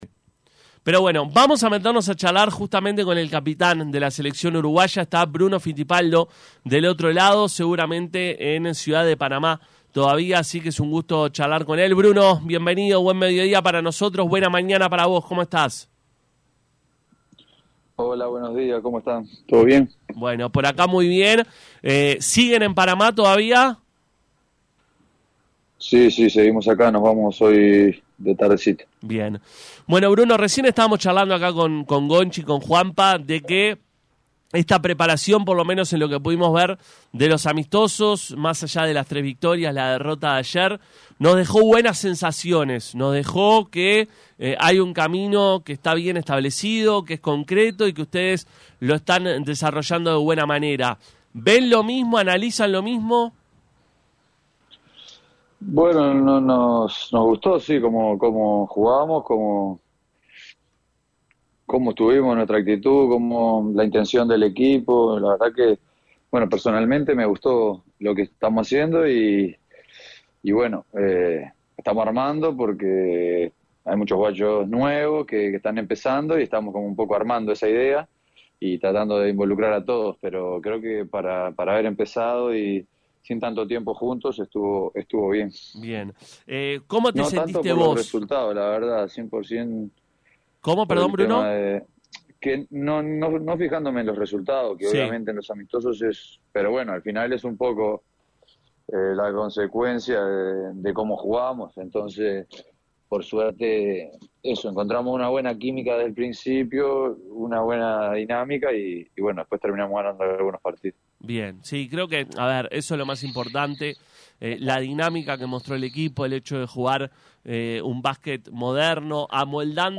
Bruno Fitipaldo capitán de la Selección Uruguaya habló con Pica La Naranja previo a la AmeriCup.